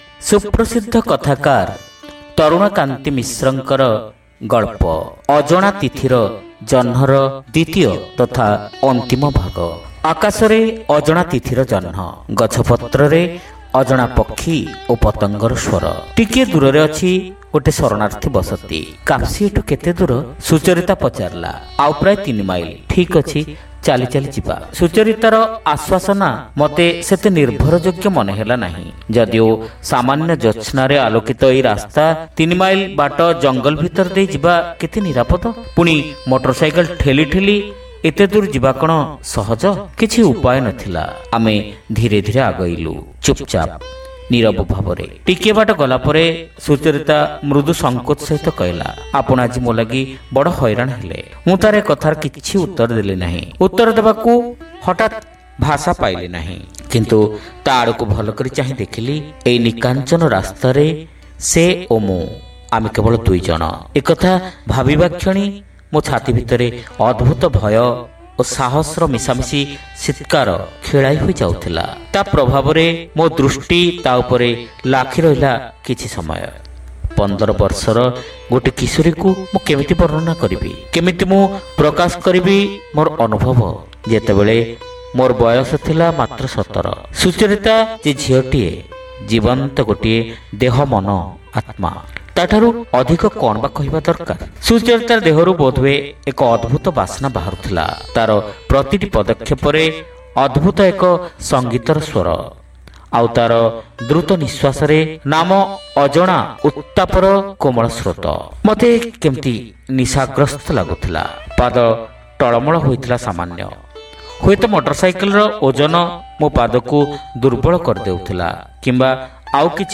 Audio Story : Ajana Tithira Janha (Part-2)